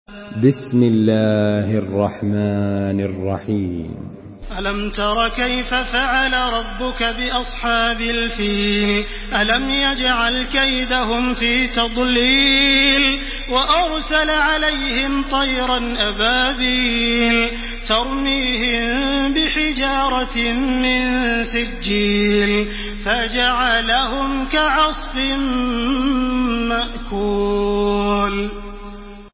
سورة الفيل MP3 بصوت تراويح الحرم المكي 1429 برواية حفص
مرتل